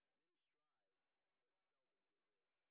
sp06_exhibition_snr10.wav